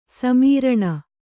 ♪ samīraṇa